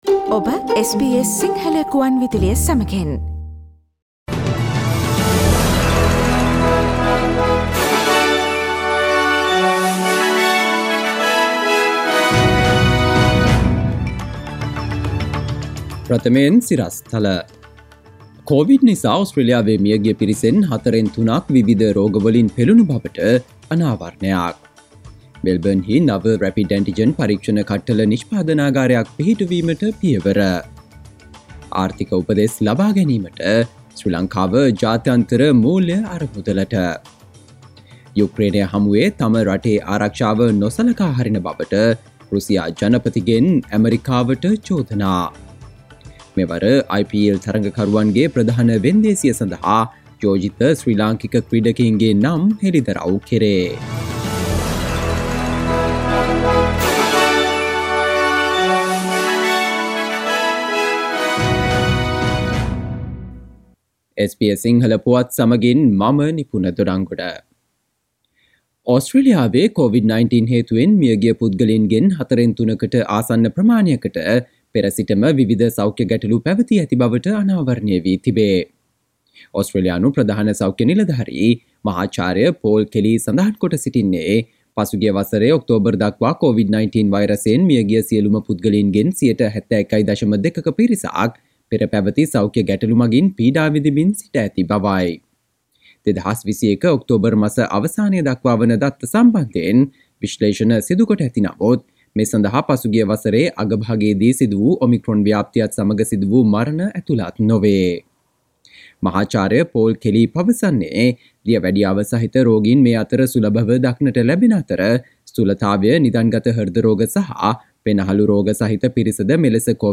සවන්දෙන්න 2022 ජනවාරි 03 වන බ්‍රහස්පතින්දා SBS සිංහල ගුවන්විදුලියේ ප්‍රවෘත්ති ප්‍රකාශයට...